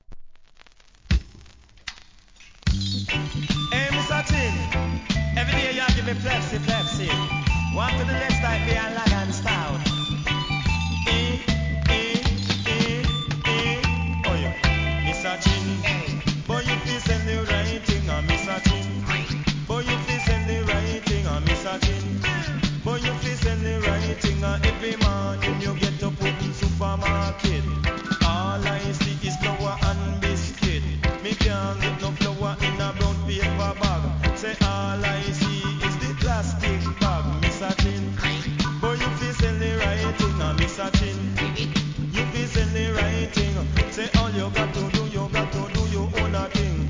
関連カテゴリ REGGAE